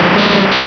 Cri de Grolem dans Pokémon Diamant et Perle.